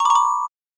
Camera Shutter 3.wav